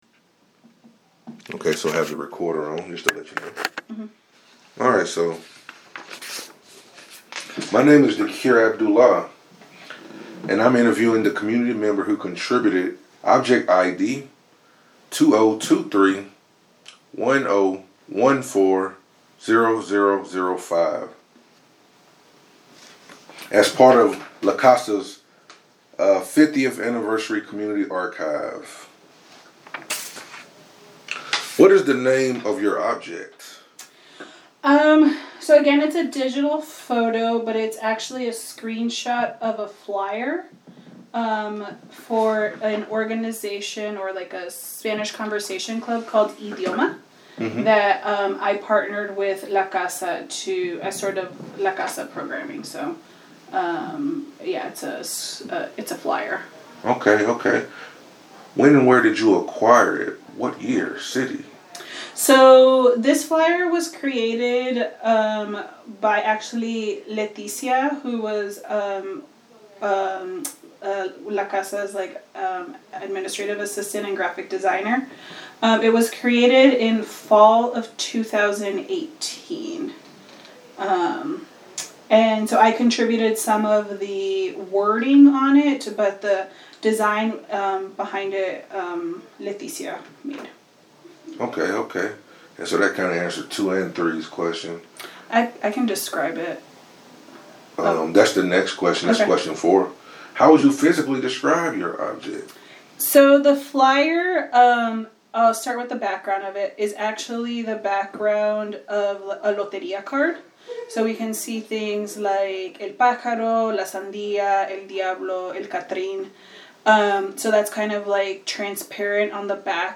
An interview with the contributor